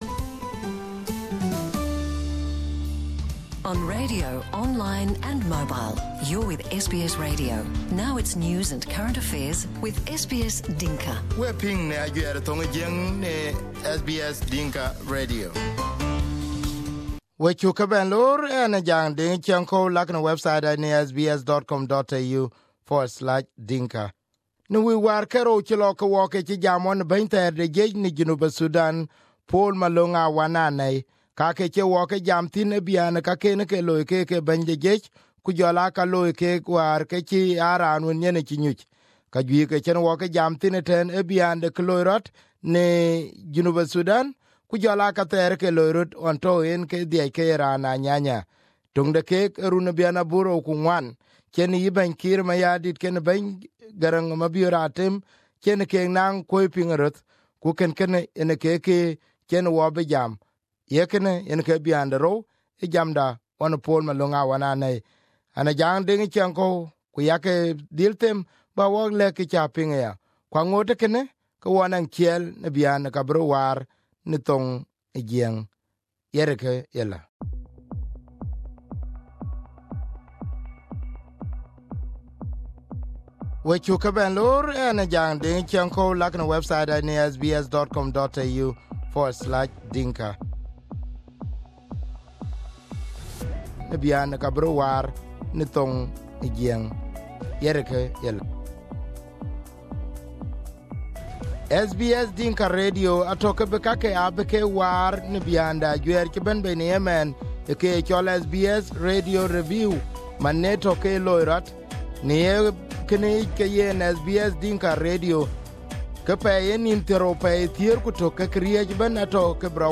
This is the part two of the interview with the former South Sudanese chief of army Pual Malong Awan Anei. In this segment, Malong talked about the role he played in 2004 that helped in reconciling Dr. John Garang and his deputy Slava Kiir Mayardit. Malong talked about his arrest and what he thinks as the reason for keeping him under house arrest.